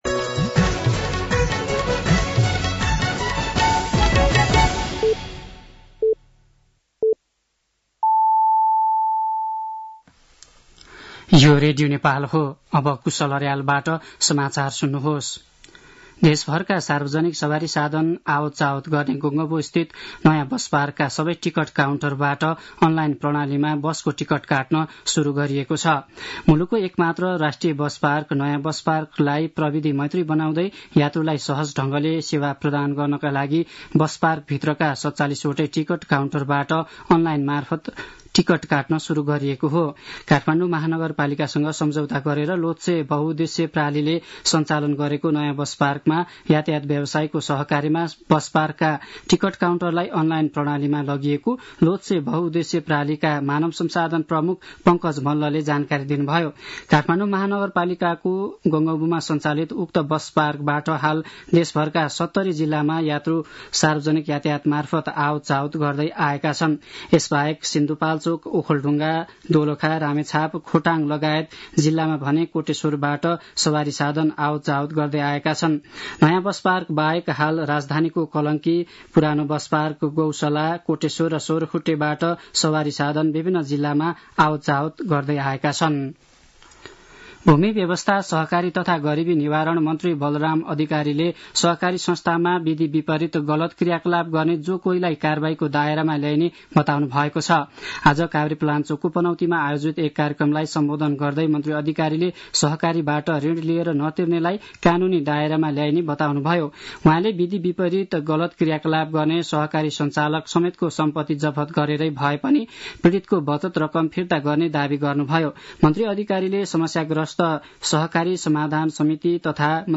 साँझ ५ बजेको नेपाली समाचार : २० फागुन , २०८१
5-pm-news-1.mp3